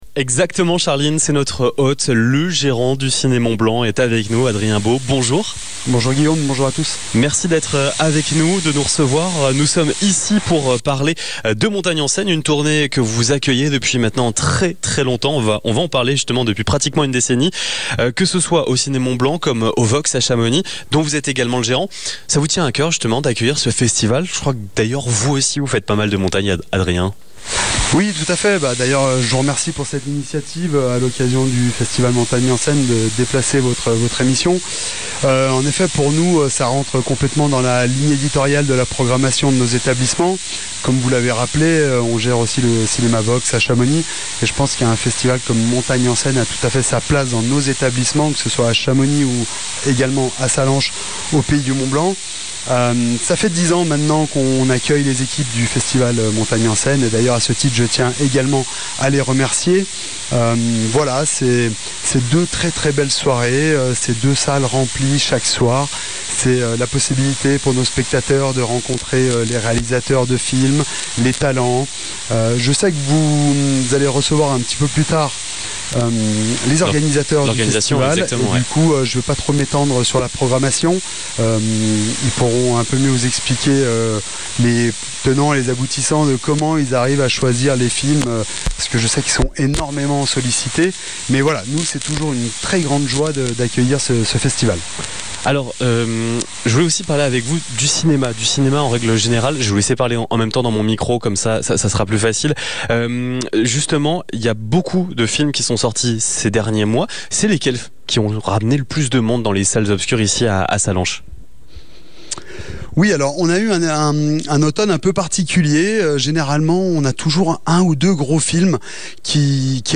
Ce mercredi 26 novembre, nous avons posé nos micros au Ciné Mont-Blanc, le multiplex emblématique de la vallée, à l'occasion du Festival Montagne en Scène.
Interview